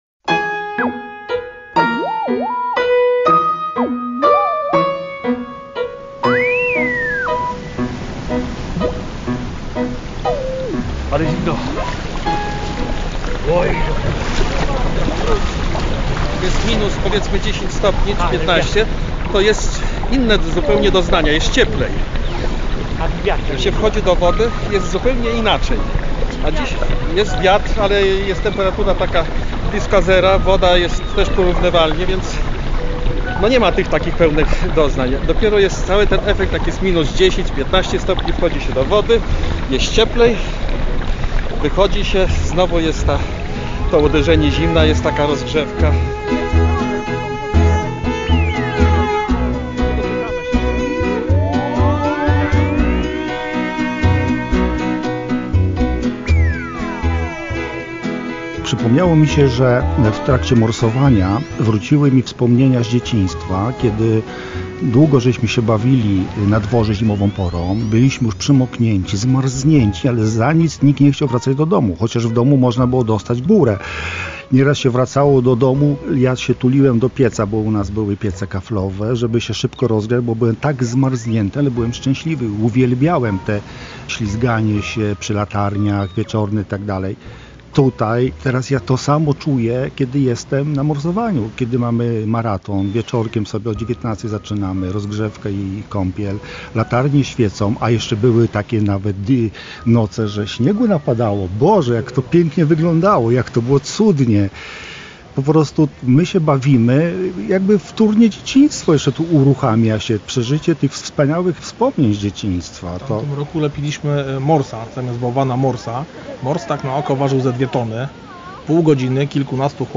Jak morsowanie wpływa na ciało i duszę? Opowiadają o tym członkowie Lubelskiego Klubu Morsów wprost z lodowatego Zalewu Zemborzyckiego.